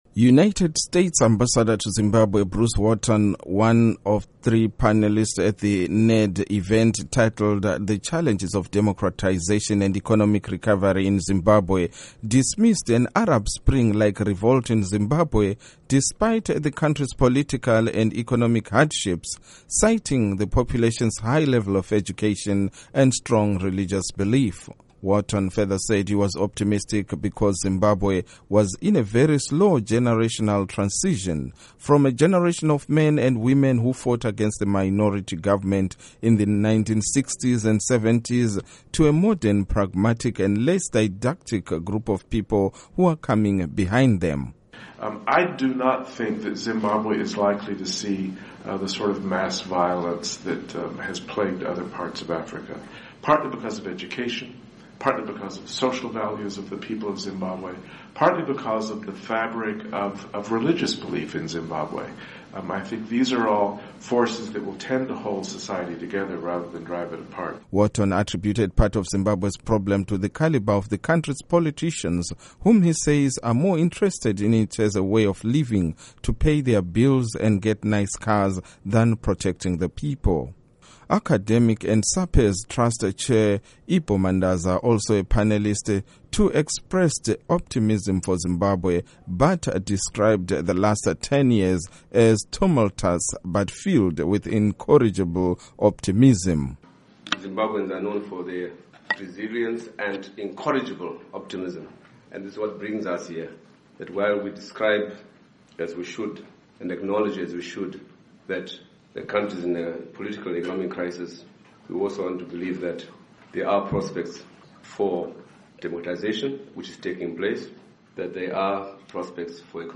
Report on Zimbabwe Democracy